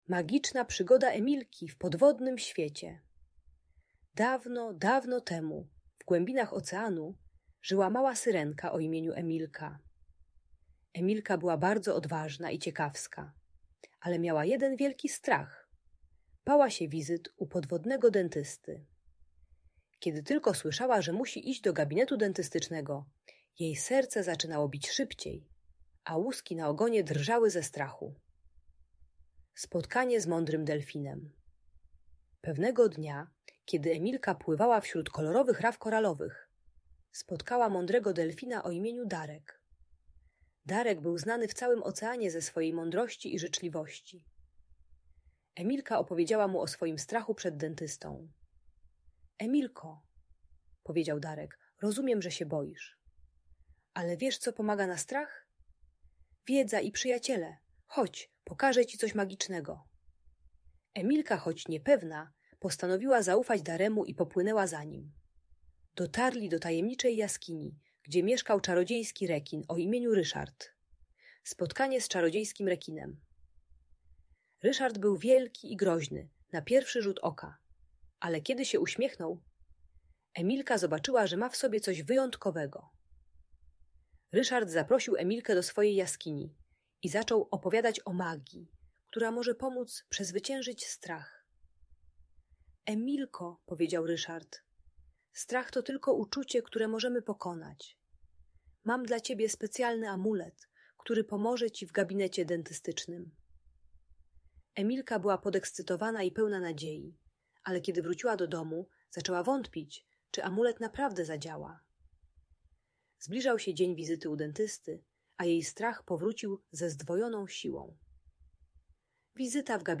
Magiczna przygoda Emilki - Lęk wycofanie | Audiobajka